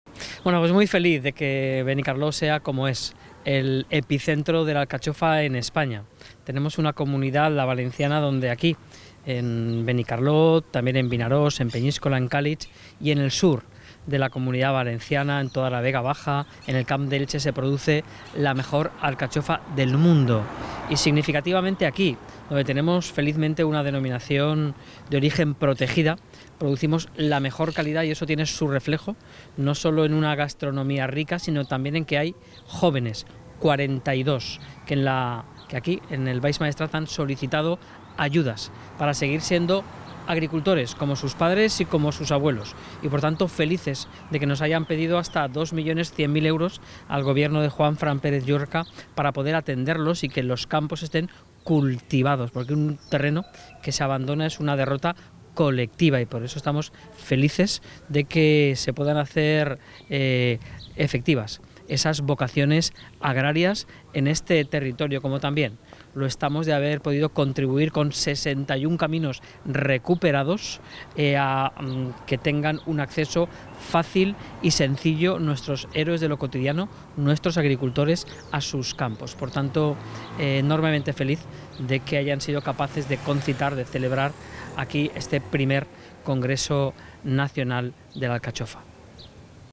Durante su intervención, Miguel Barrachina ha defendido la importancia de “mirar la etiqueta” como un gesto sencillo que permite al consumidor identificar el origen y la calidad del producto y, al mismo tiempo, reconocer el trabajo del agricultor.
El conseller ha puesto en valor el I Congreso Nacional de la Alcachofa como un espacio útil para compartir soluciones reales a los retos del sector.